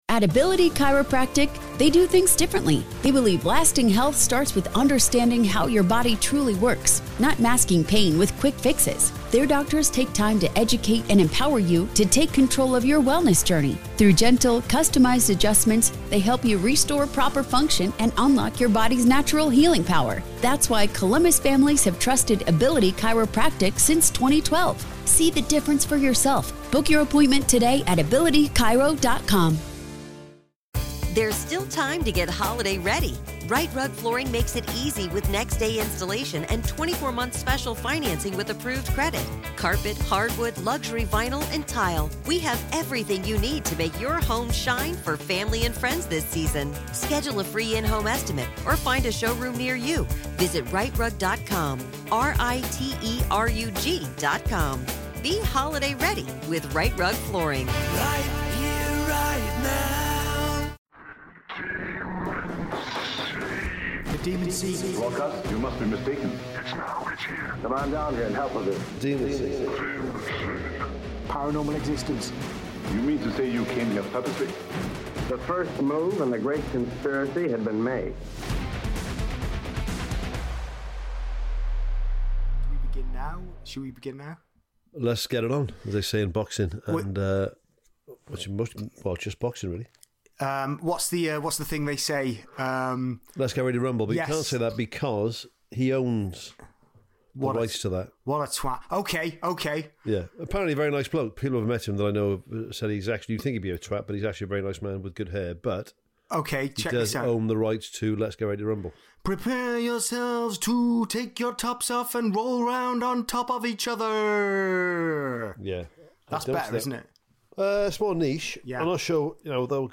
We are back, with 5% paranormal content vs 95% accents and songs.